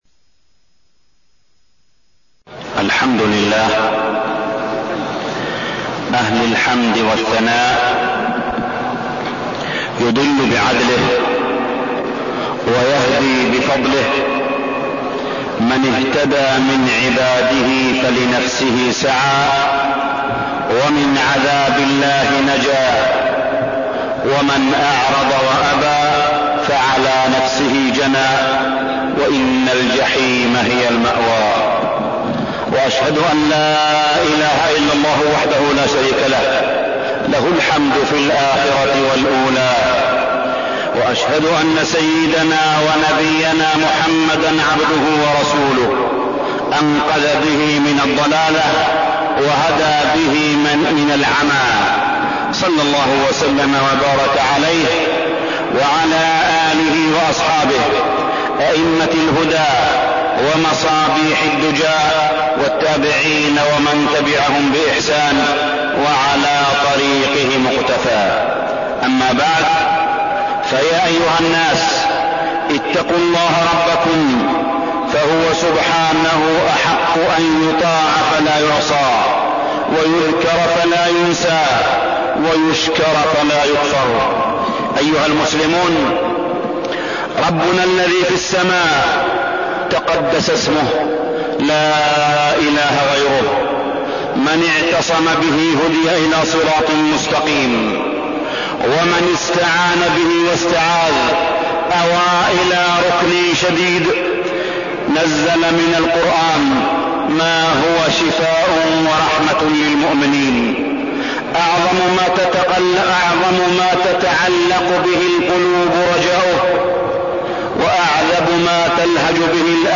تاريخ النشر ١٤ ذو القعدة ١٤١٥ هـ المكان: المسجد الحرام الشيخ: معالي الشيخ أ.د. صالح بن عبدالله بن حميد معالي الشيخ أ.د. صالح بن عبدالله بن حميد صلاح المعتقد